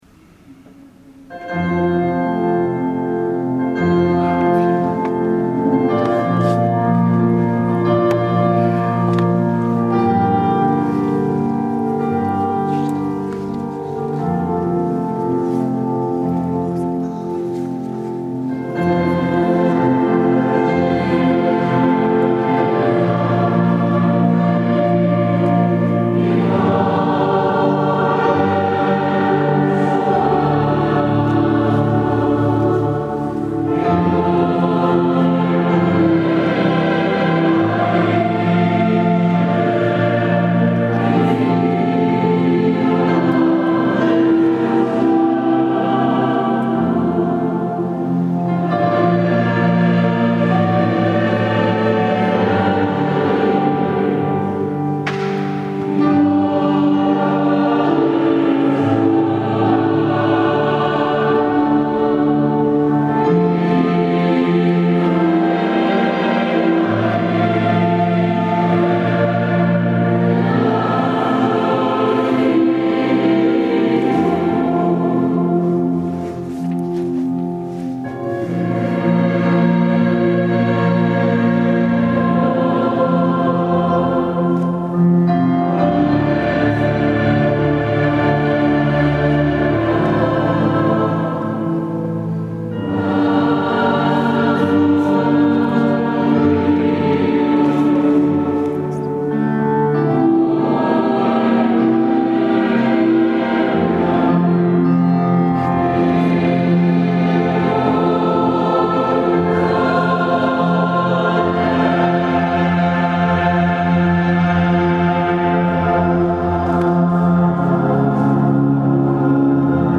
Sabato 07 ottobre 2017 la corale ha animato la S. Messa in occasione della festività della Madonna del Rosario.